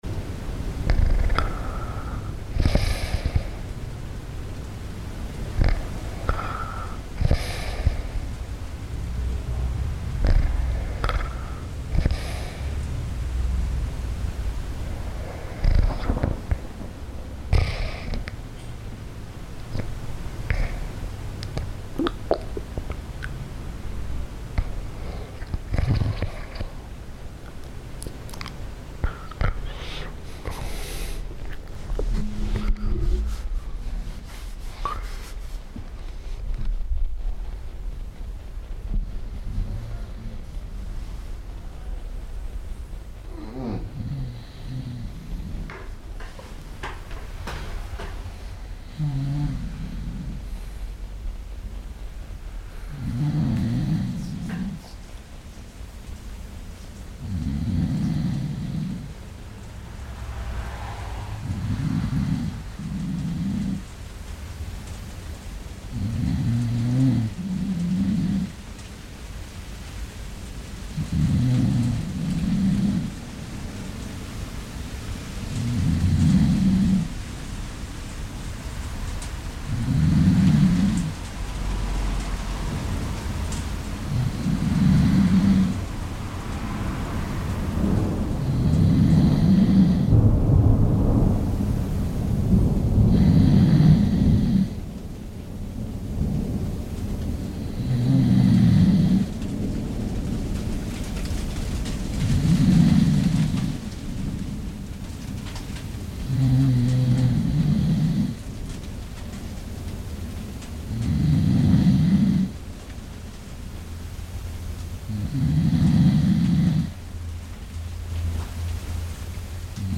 live streamed
saxophone and electronics
Saxophone and Ladder